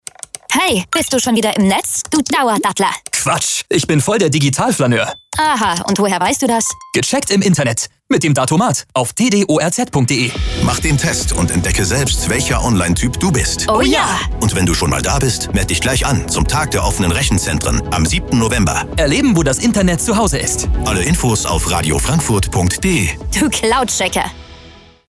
Bestimmt habt ihr schon den Spot bei uns im Radio gehört und fragt euch sicher, ob ihr ein Cyber-Ninja, ein Digitaler Diplomat oder ein Digital-Flaneur seid.
TdoRz-Funkspot-Motiv2-V2-Frau_Mann.mp3